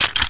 Click.wav